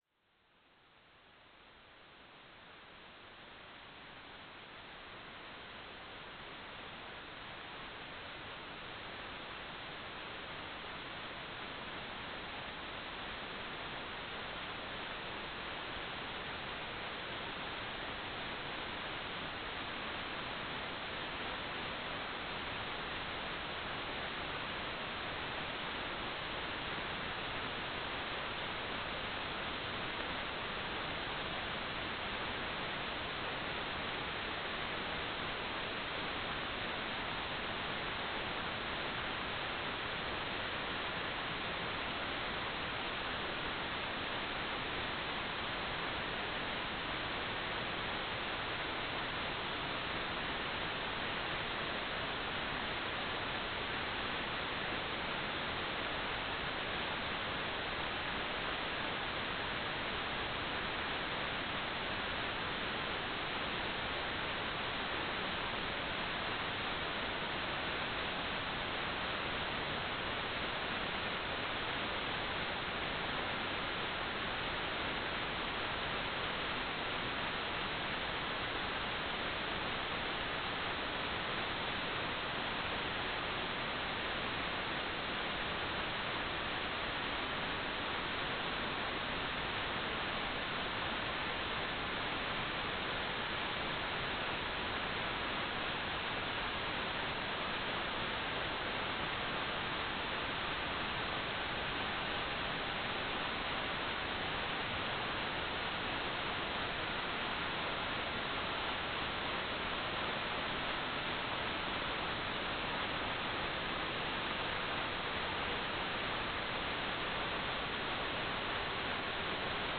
"transmitter_mode": "CW",